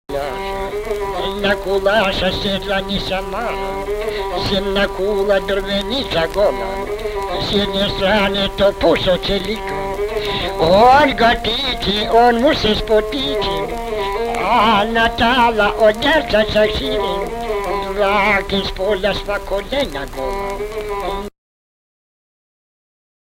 Omdat muzikale begeleiding in het gesproken woord een grote rol speelde (hier stukjes uit een
voordracht op de Balkan), is bij iedere lezing muziek te horen.